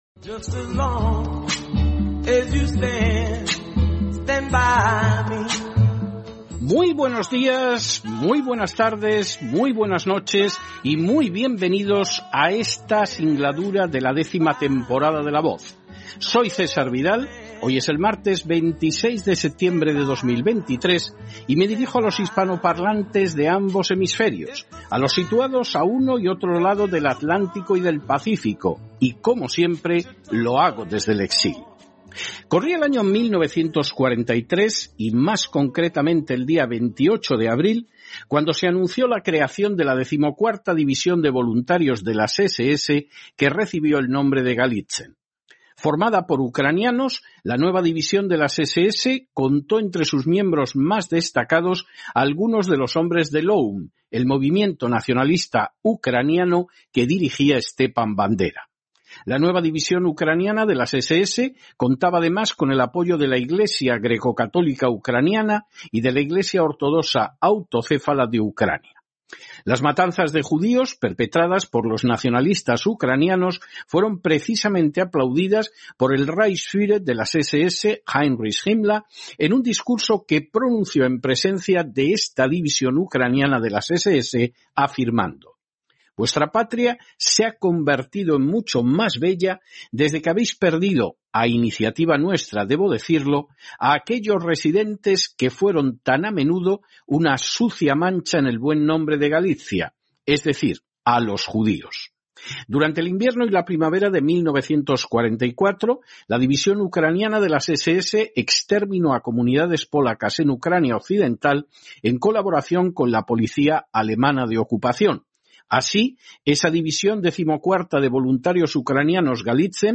editorial-zelenski-aplaude-publicamente-a-un-oficial-de-la-ss.mp3